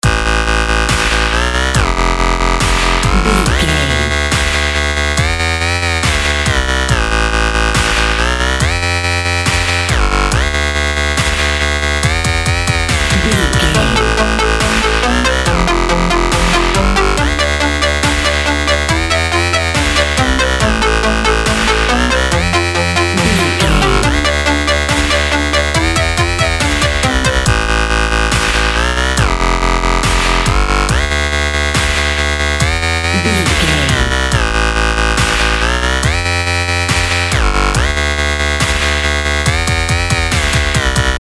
Explosive Dubstep.
Epic / Action
Fast paced
Aeolian/Minor
aggressive
powerful
dark
driving
energetic
intense
drum machine
synthesiser
electronic
synth lead
synth bass
synth drums